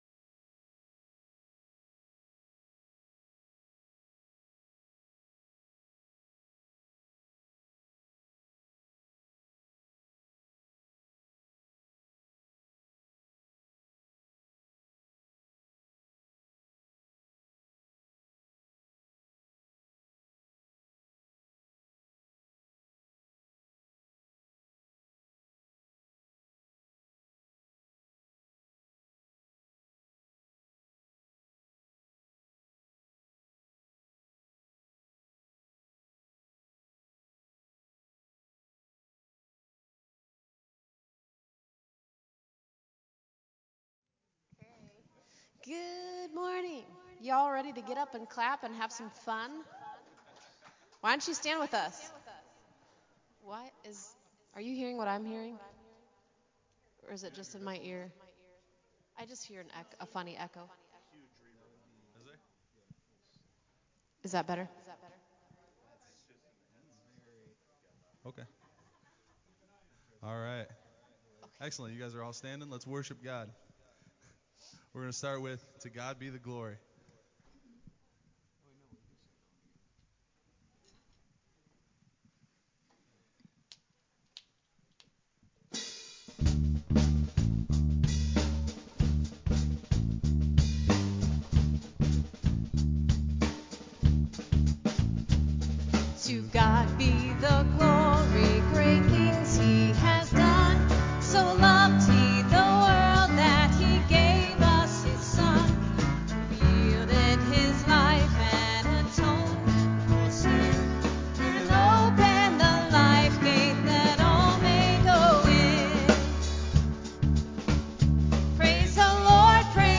Worship on March 20th 2022
Announcements and Welcome
Prayer Requests and Praises